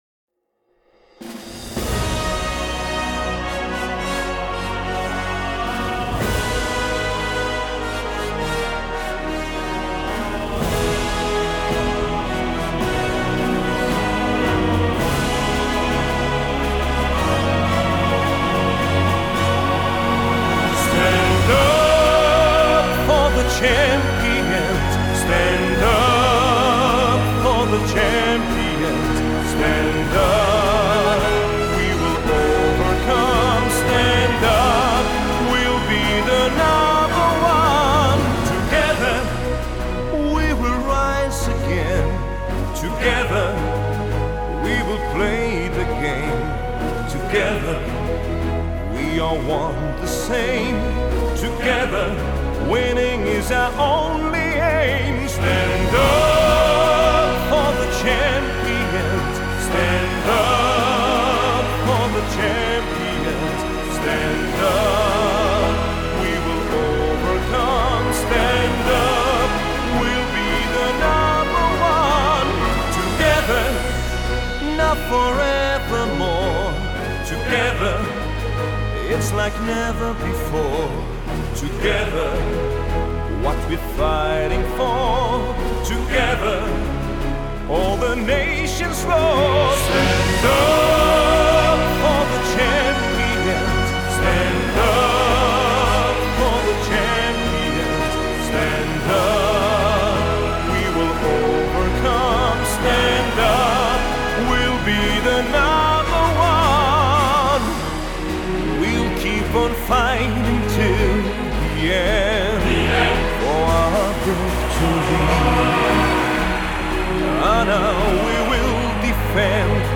[人声单曲]